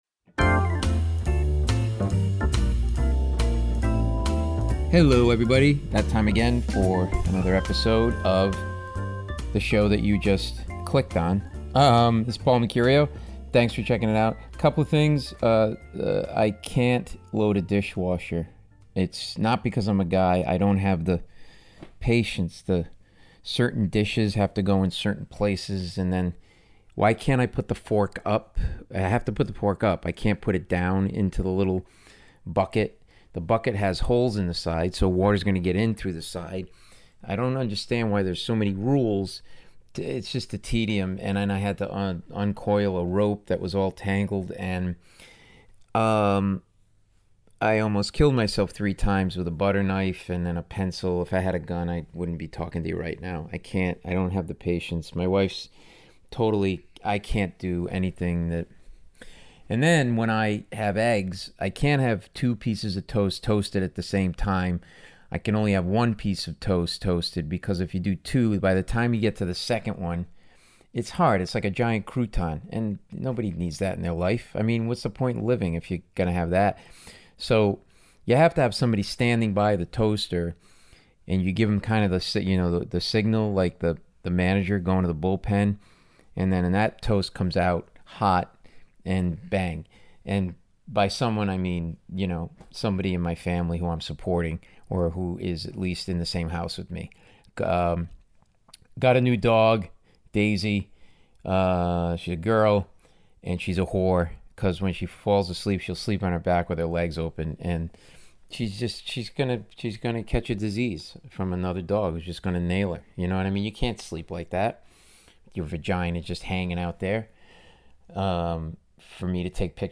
We have alotta laughs along the way as we realize I'm hopeless.